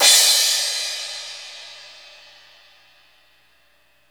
CYM XCRASH0L.wav